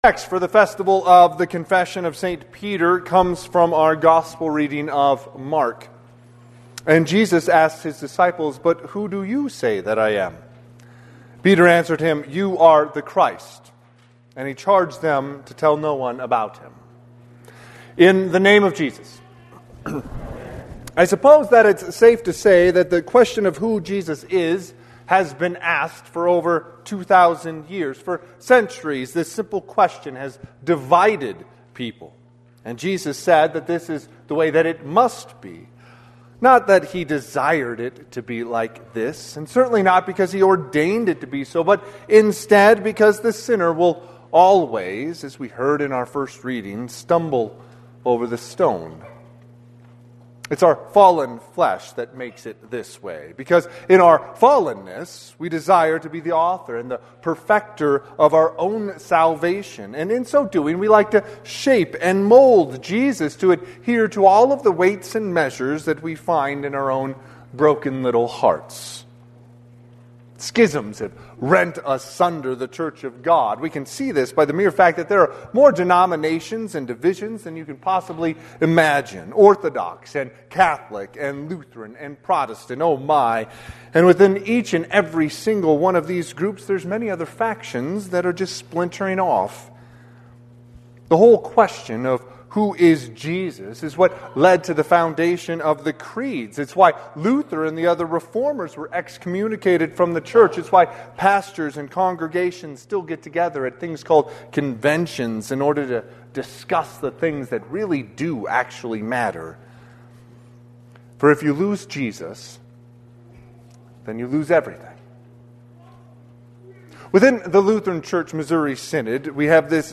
Sermon - 1/18/2026 - Wheat Ridge Evangelical Lutheran Church, Wheat Ridge, Colorado